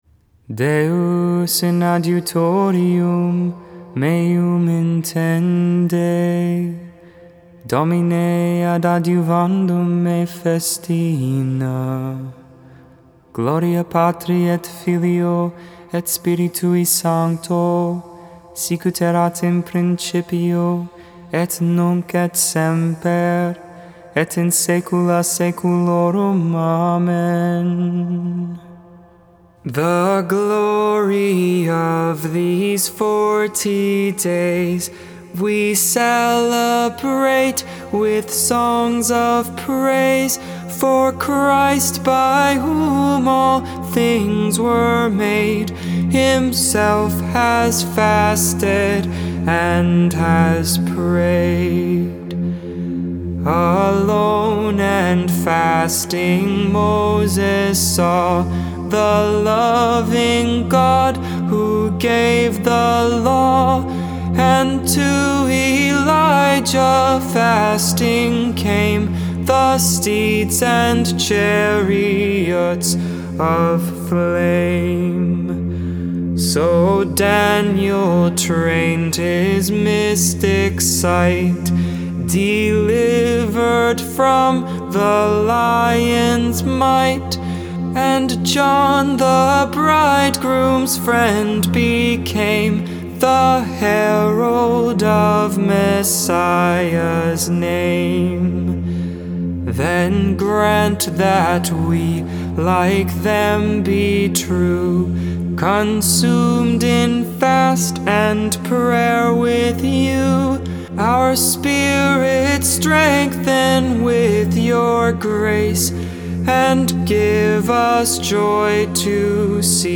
2.21.21 Lauds, Sunday Morning Prayer